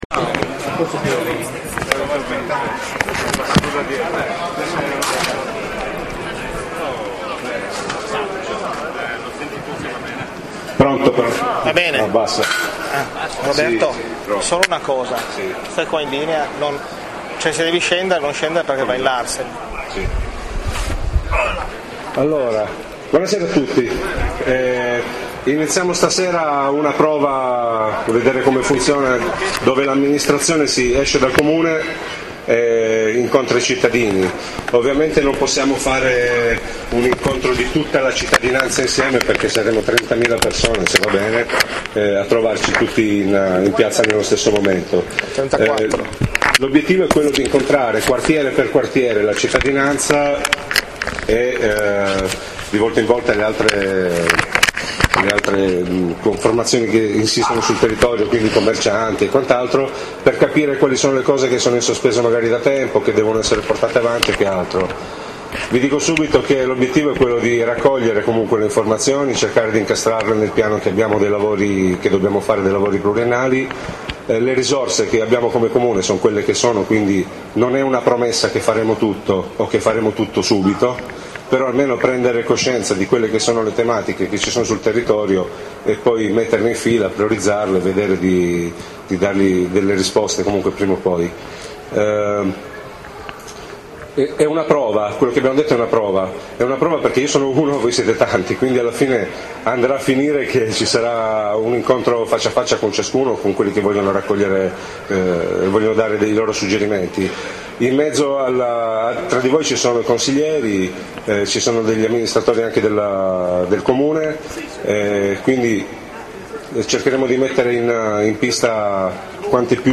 I cittadini del quartiere Centro Storico – Pre Parco hanno accolto con favore l’incontro voluto dal sindaco Roberto Falcone, che si è tenuto mercoledì 7 ottobre scorso in piazza Annunziata. Circa 200 persone si sono riunite per esprimere le loro domande e richieste: dalla mancanza di lavoro, sentito da molti cittadini, alla fruibilità del Centro Storico e alla viabilità di via Mensa, alla richiesta di maggiore mobilità verso Torino: qui il sindaco esprimeva la volontà di aprire nei prossimi giorni un tavolo di discussione col sindaco di Torino, Fassino per valorizzare la Torino-Ceres e farla diventare linea metropolitana.
Oltre venti cittadini si sono avvicinati al microfono per chiedere direttamente al sindaco come poter risolvere le tante questioni poste.